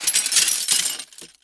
GLASS_Fragments_Fall_01_mono.wav